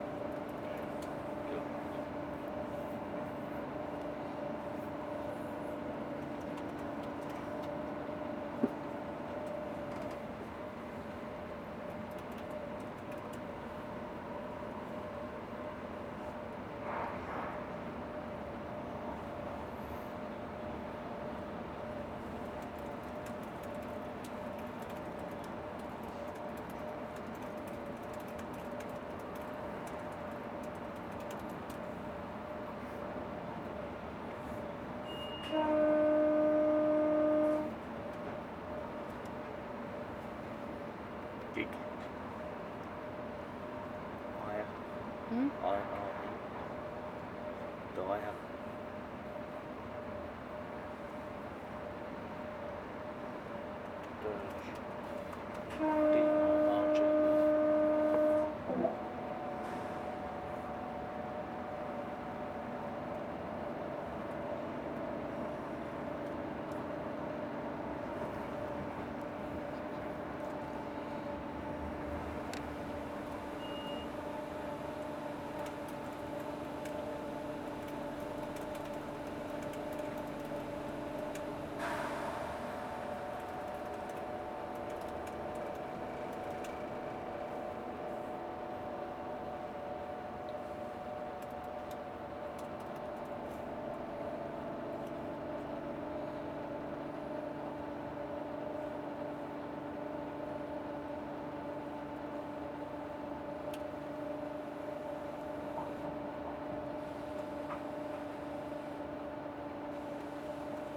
On a train
Threads of noise, tape loops, and samples.